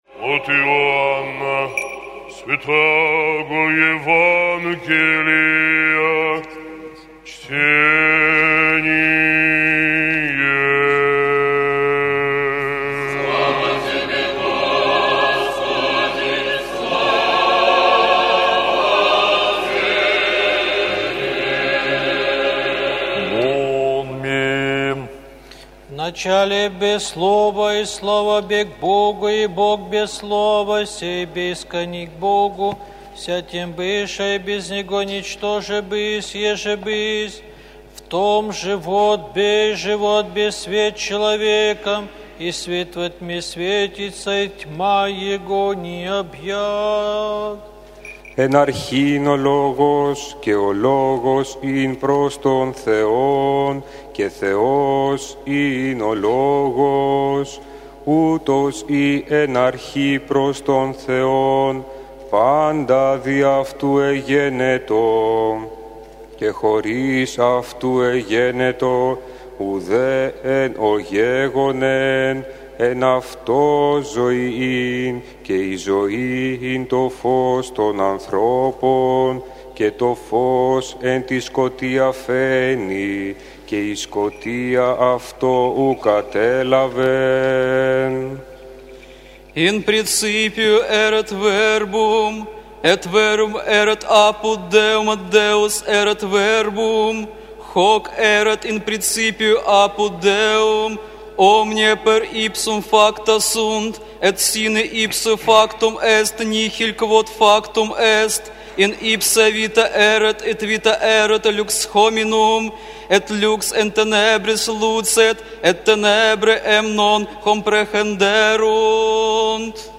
Пасхальное евангелие на четырёх языках - Кафедральный собор Христа Спасителя г. Калининграда